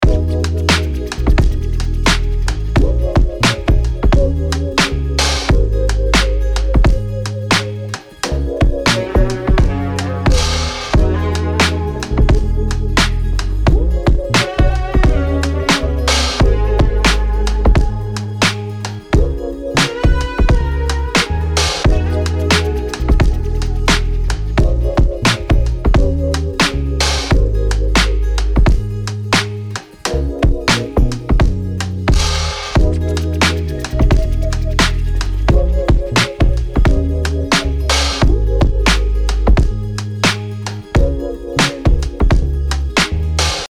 royalty-free drum breaks, percussion loops and one-shots
Explosive Drum Sounds